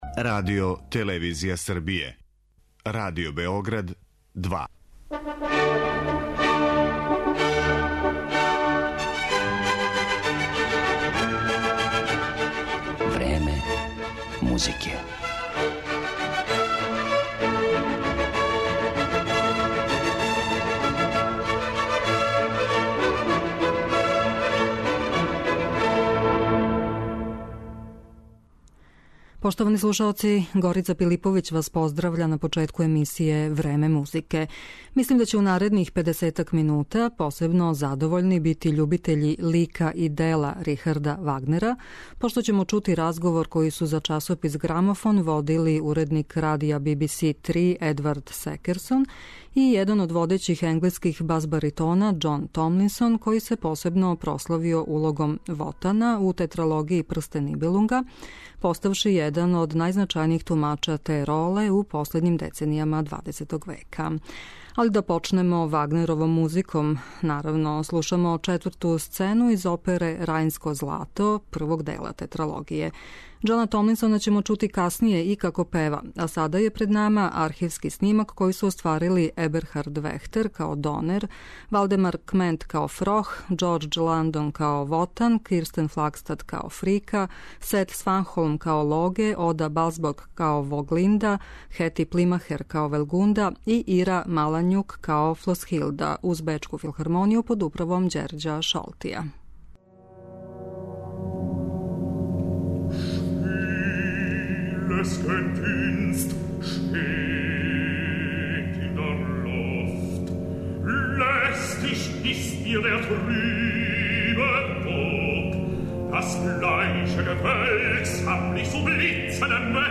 Разговор о Вагнеру